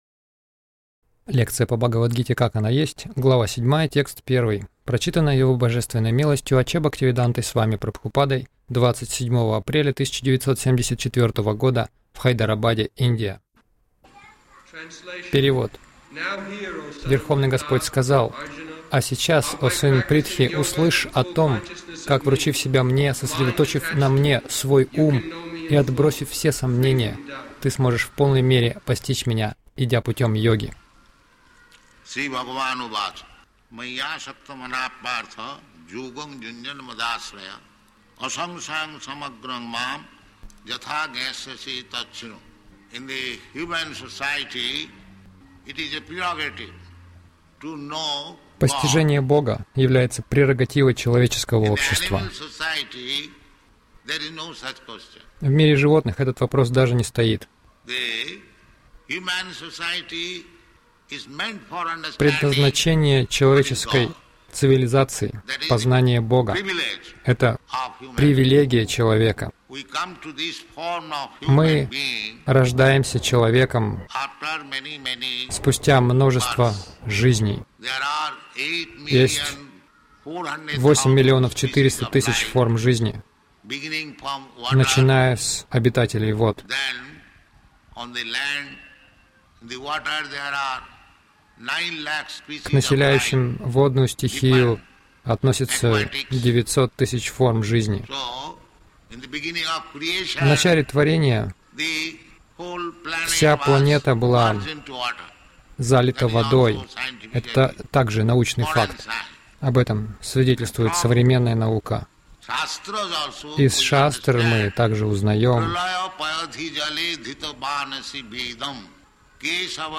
Милость Прабхупады Аудиолекции и книги 27.04.1974 Бхагавад Гита | Хайдарабад БГ 07.01 — Я — душа, а не Высшая Душа.